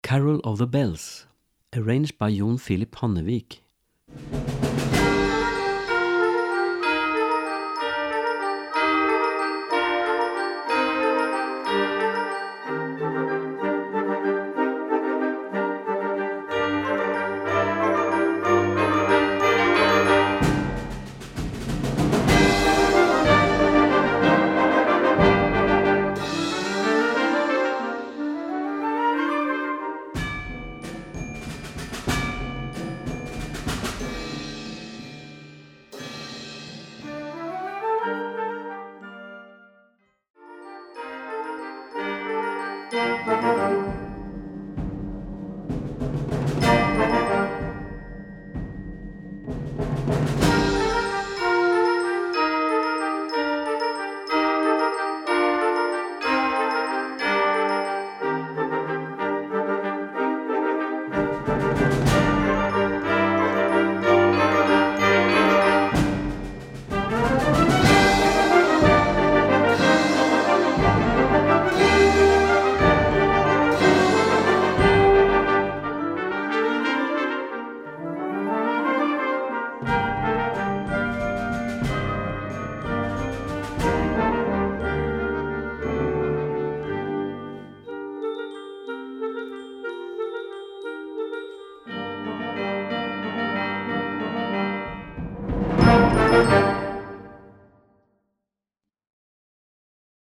Gattung: Weihnachtsmusik für Blasorchester
Besetzung: Blasorchester